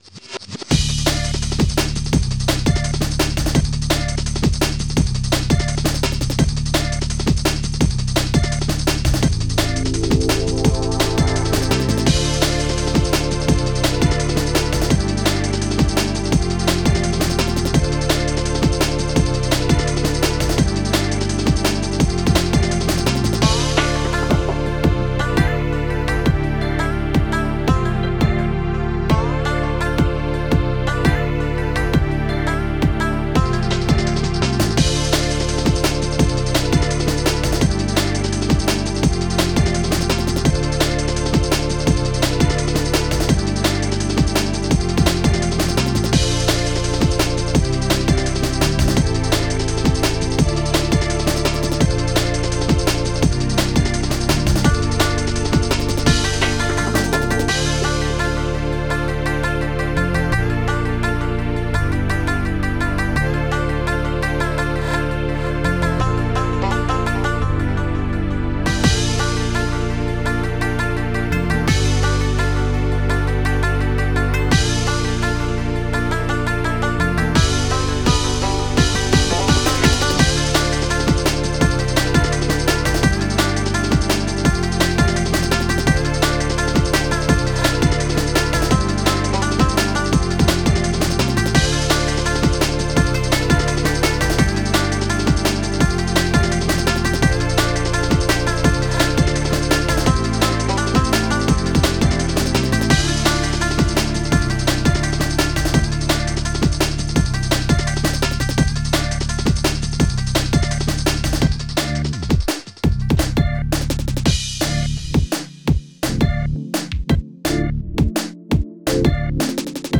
Drum & Bass